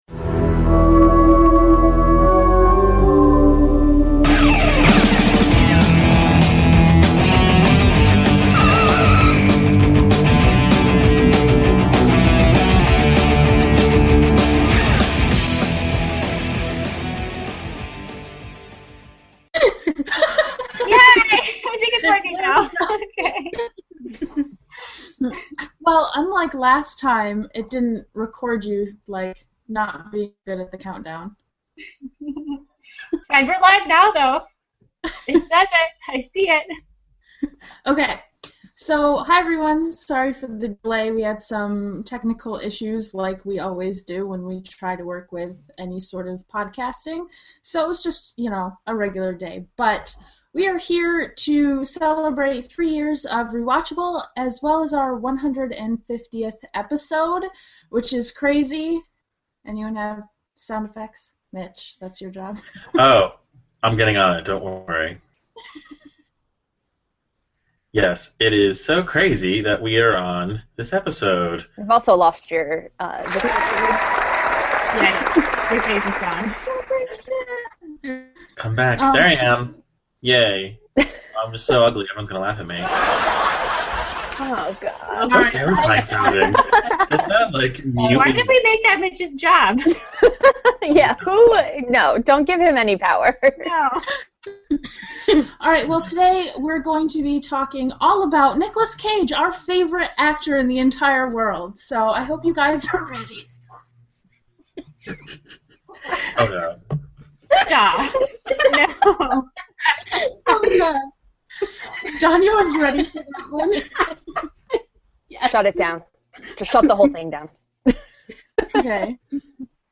Past and present hosts come together to celebrate three years of ReWatchable in a delightful, disastrous livestream!